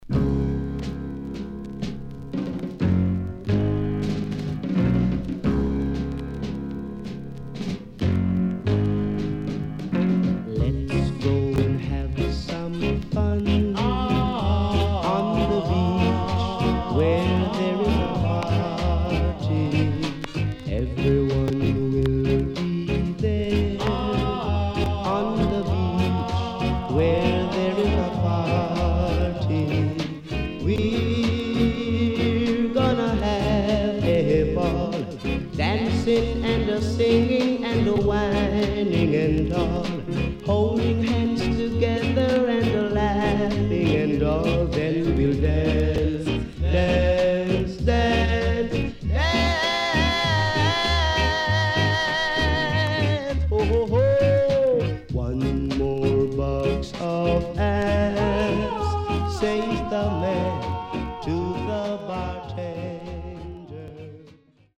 SIDE A:所々チリノイズ、プチノイズ入ります。